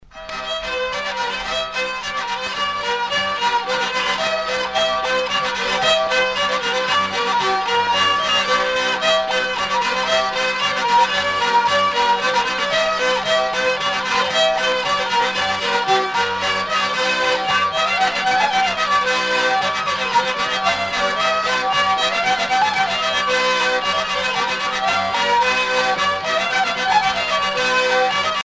danse : polka
Pièce musicale éditée